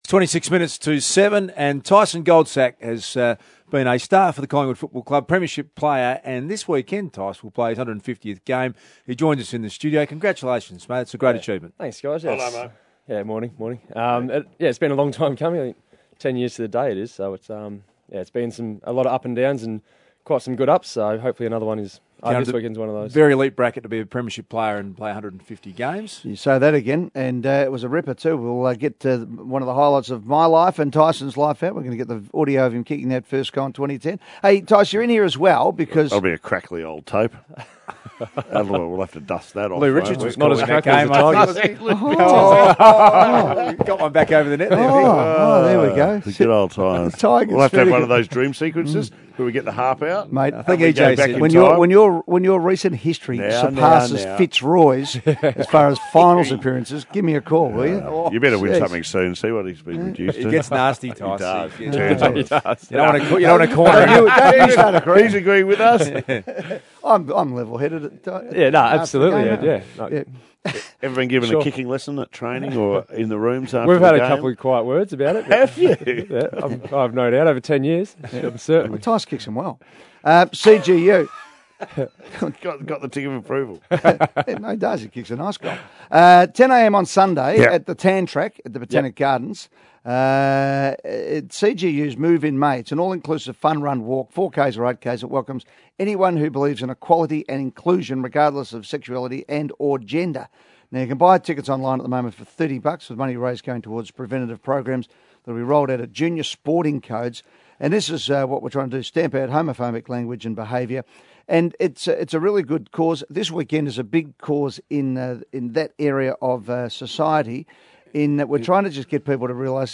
Listen to Tyson Goldsack as he spoke to Triple M's Hot Breakfast on Thursday 18 May.